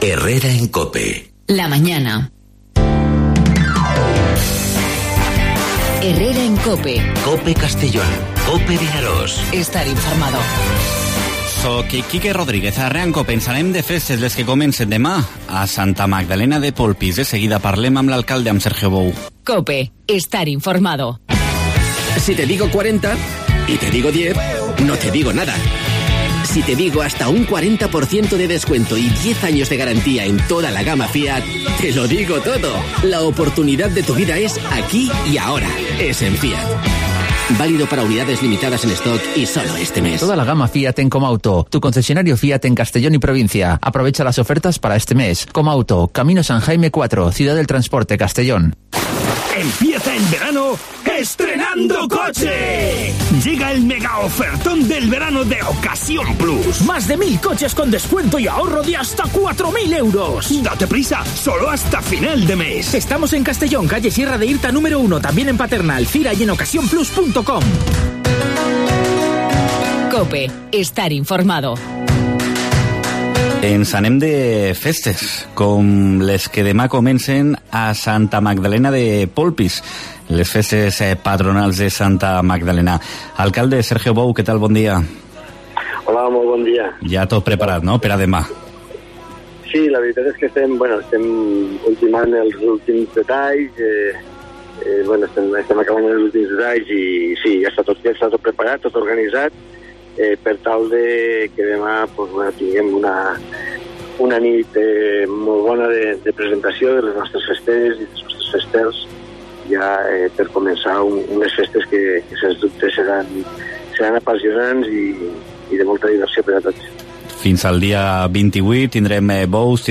AUDIO: Santa Magdalena de Pulpis inicia este fin de semana sus fiestas patronales, que presenta en 'Herrera en COPE' su alcalde, Sergio Bou, que...